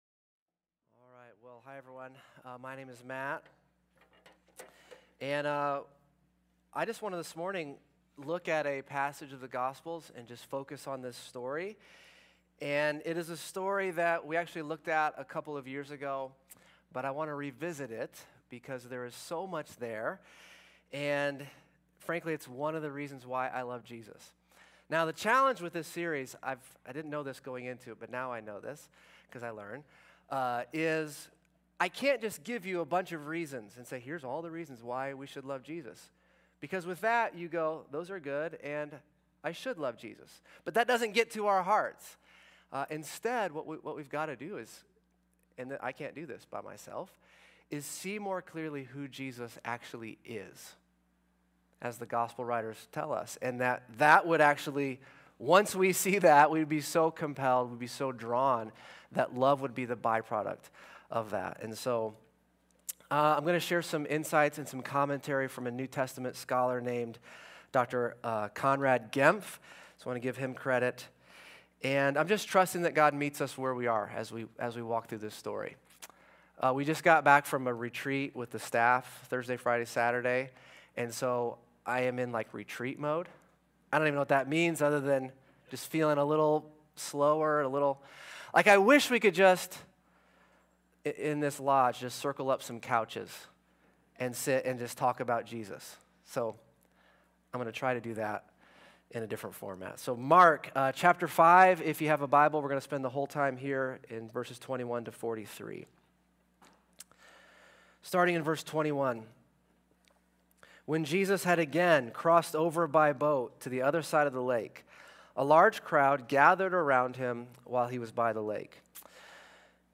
A message from the series "Why I Love Jesus."